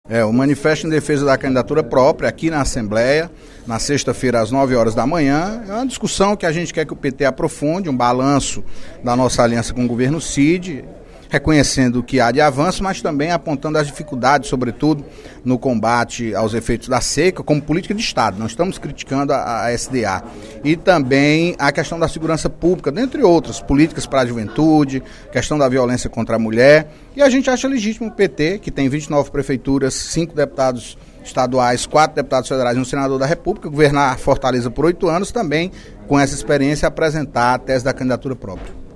O deputado Antonio Carlos (PT) convidou os militantes petistas, durante o primeiro expediente desta quinta-feira (13/02), para o lançamento do manifesto de um segmento do Partido dos Trabalhadores defendendo candidatura própria para a disputa do Governo do Estado nas eleições deste ano.